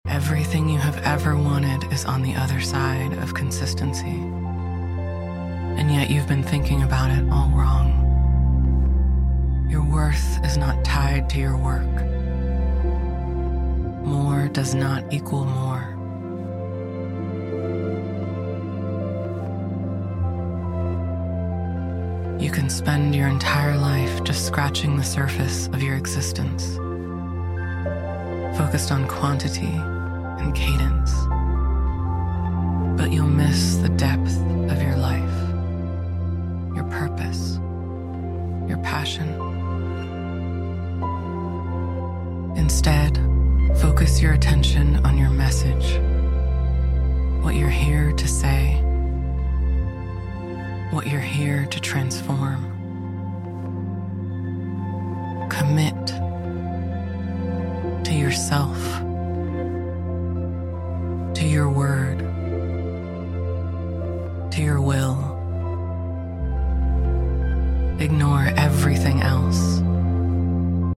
--- This is an excerpt from my latest podcast episode/meditation, out now on all platforms.